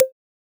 click-short-confirm.wav